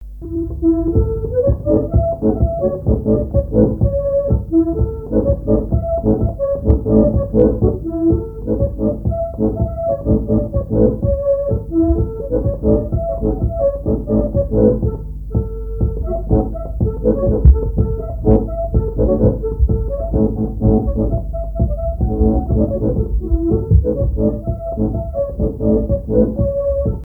Mémoires et Patrimoines vivants - RaddO est une base de données d'archives iconographiques et sonores.
danse : polka des bébés ou badoise
Répertoire à l'accordéon diatonique
Pièce musicale inédite